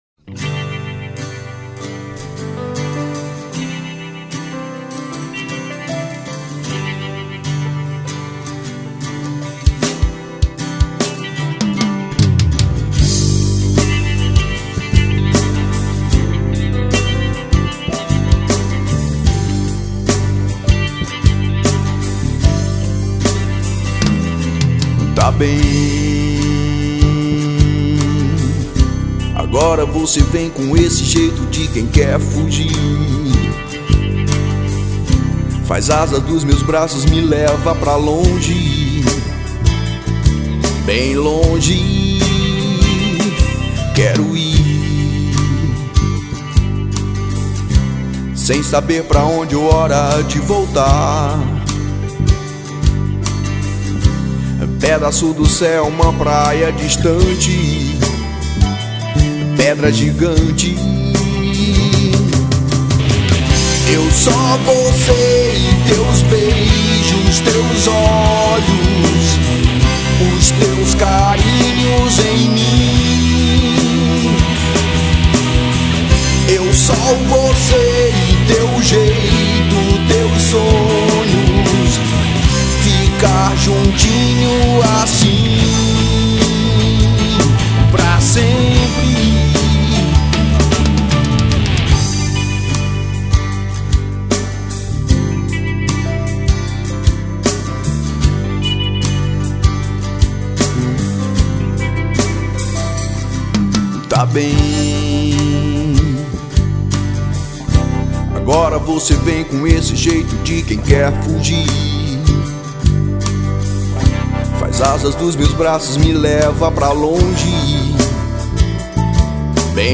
1964   03:57:00   Faixa:     Rock Nacional